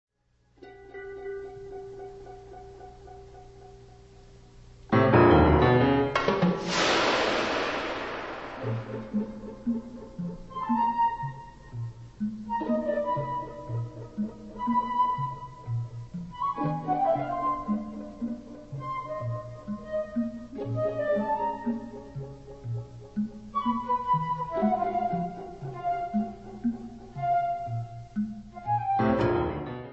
Its very… alien, other worldly.